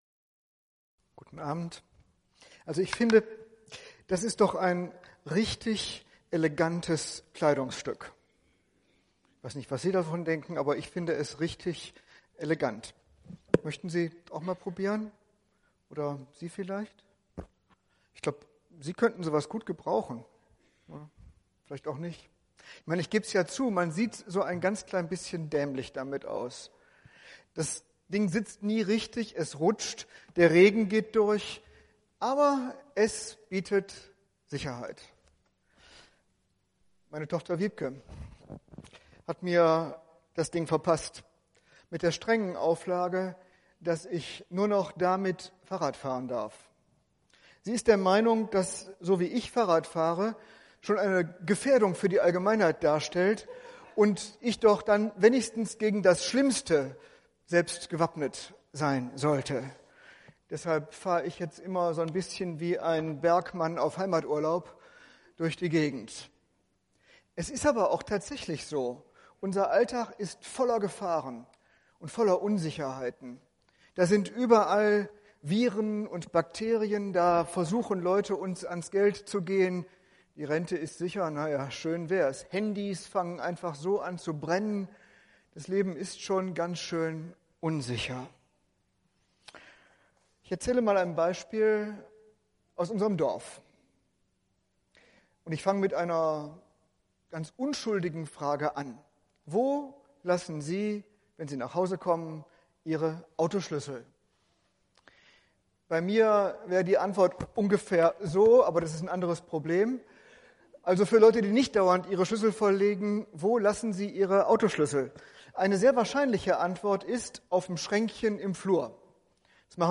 Eine predigt aus der serie "GreifBar Stadthalle."
Veranstaltungen im Rahmen von Greifbar in der Stadthalle